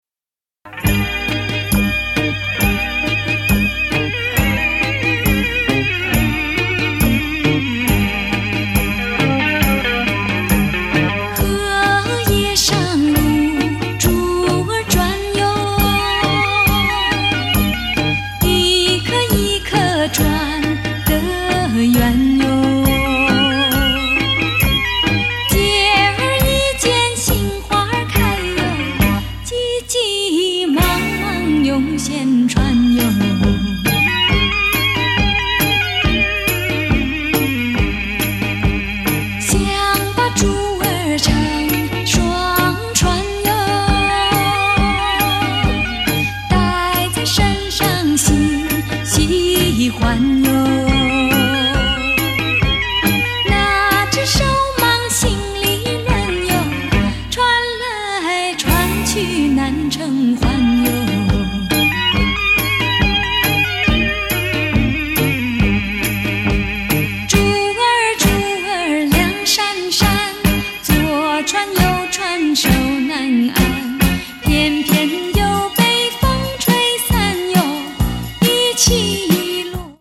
★ 虛無縹緲的天籟嗓音，蘊涵夢幻般的極致柔美！
★ 細膩幽邃的優雅歌聲，瀰漫氤氳般浪漫的韻味！